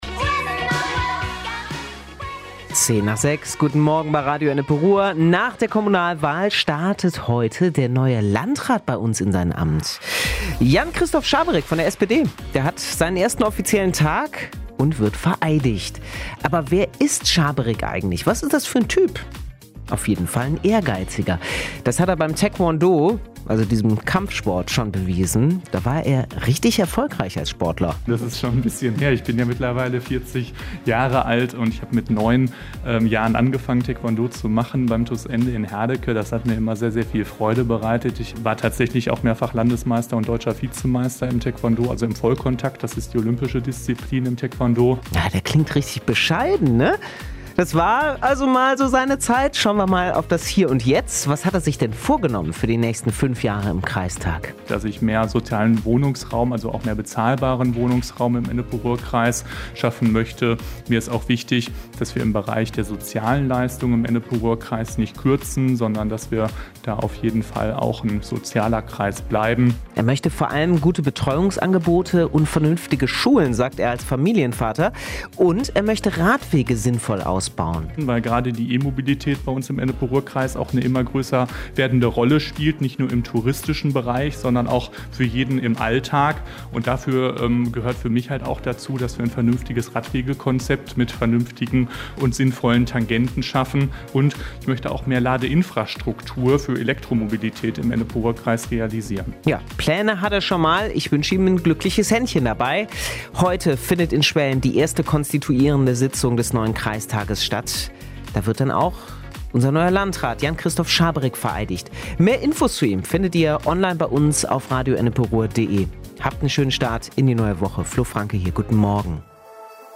Heute tritt Jan-Christoph Schaberick sein Amt als neuer Landrat des Ennepe-Ruhr-Kreises an. Wie er tickt, was er sich für seine Amtszeit vornimmt und wie er auf die angespannte Finanzlage in den Städten und bei uns im Kreis schaut, findet ihr im großen Interview zu seinem Amtsantritt.